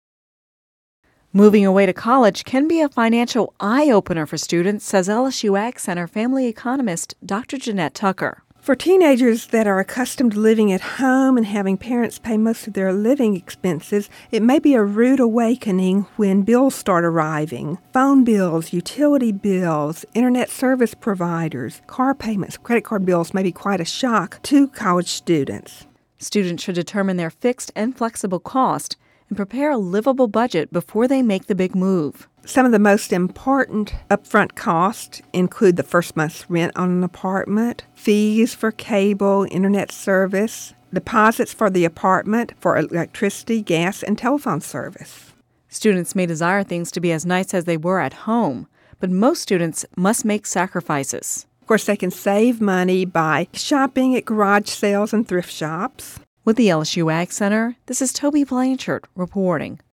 Radio News 08/23/10